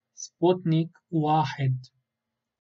العربية: لفظ كلمة سبوتنك 1 بالعربية English: Arabic pronunciation of "Sputnik-1".